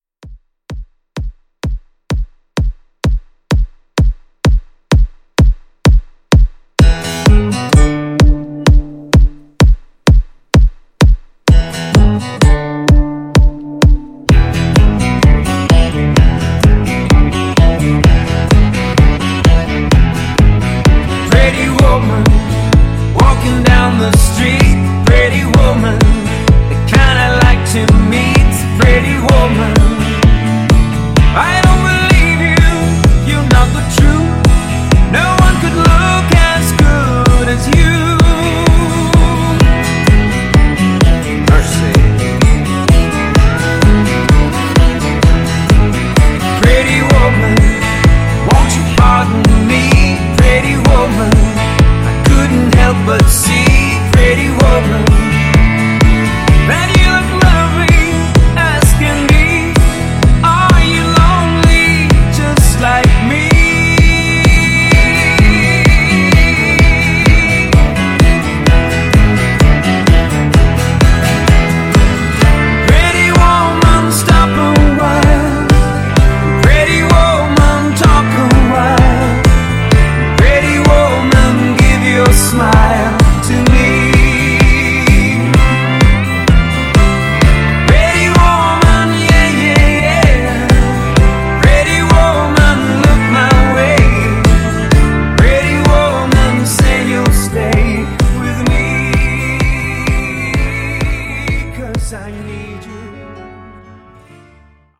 Genre: MOOMBAHTON
Dirty BPM: 102 Time